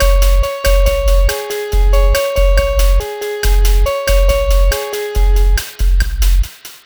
Cheese Lik 140-C#.wav